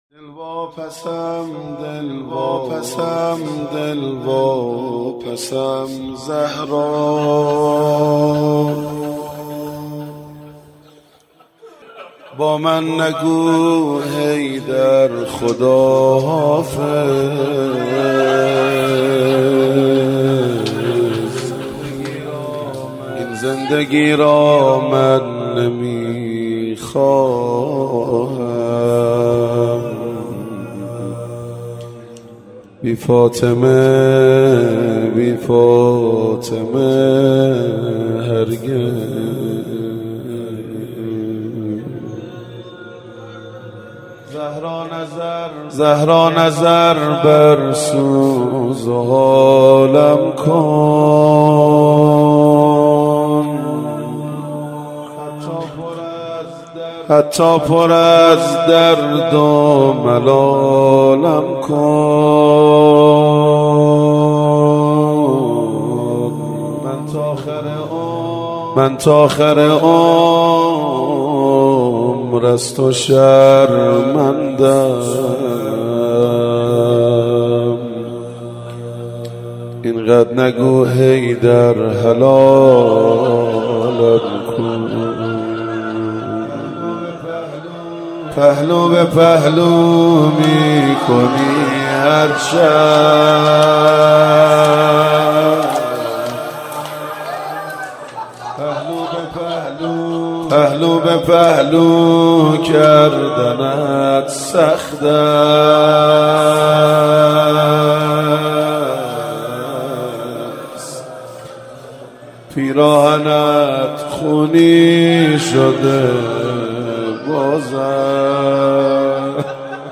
روضه خوانی